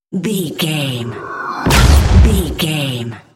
Airy whoosh explosion hit
Sound Effects
Atonal
dark
intense
woosh to hit